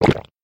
Звук жадного глотка воды